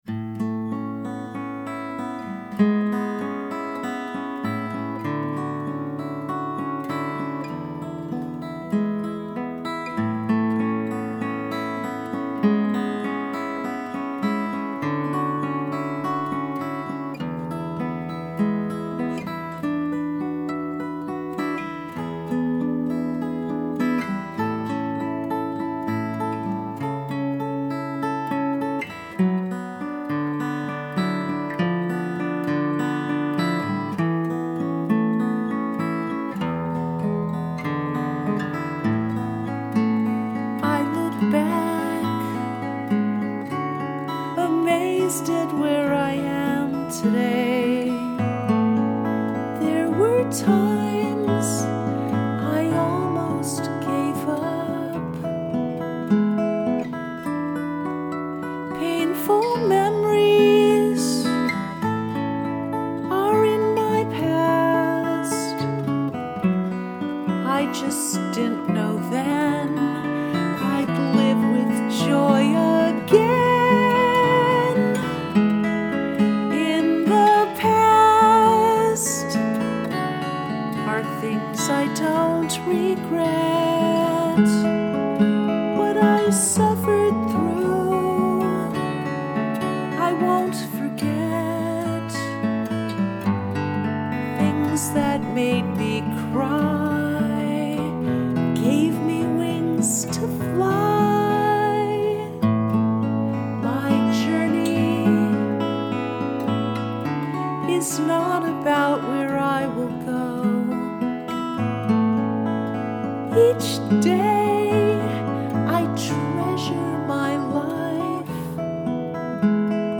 Home Recording